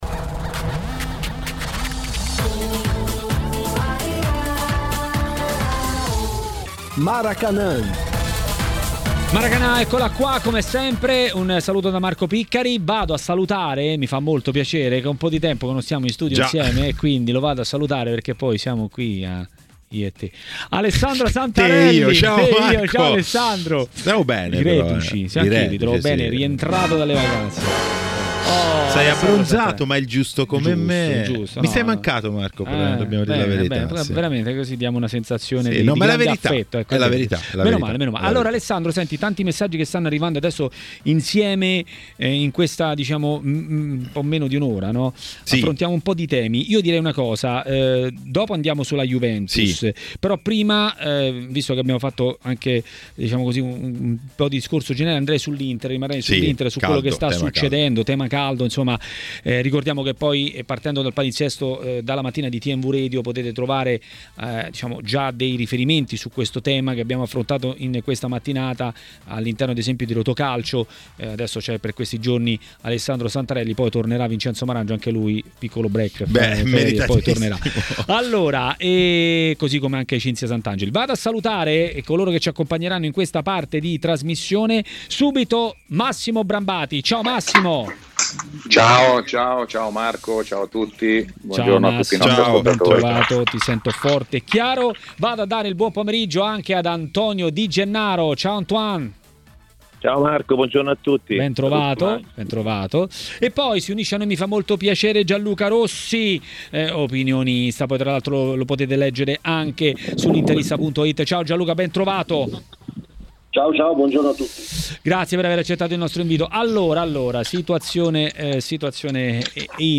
A dire la sua sui temi di mercato a TMW Radio, durante Maracanà, è stato l'ex calciatore e opinionista tv Antonio Di Gennaro.